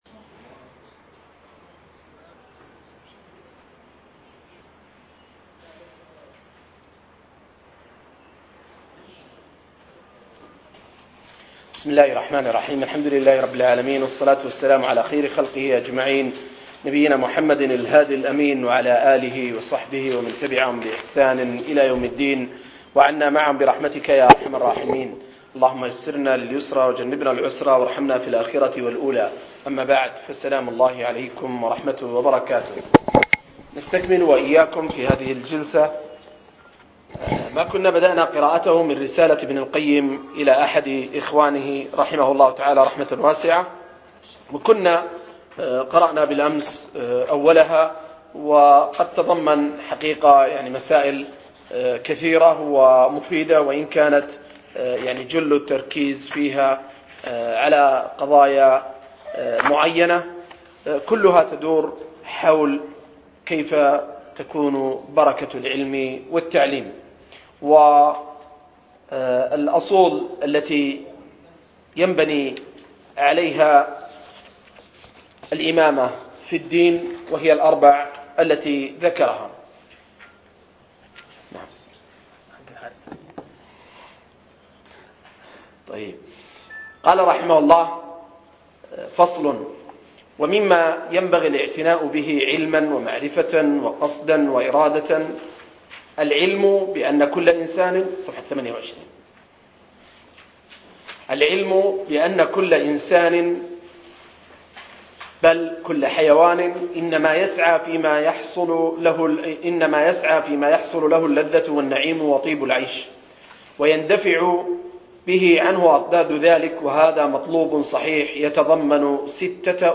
02- رسالة ابن القيم إلى أحد إخوانه – قراءة وتعليق – المجلس الثاني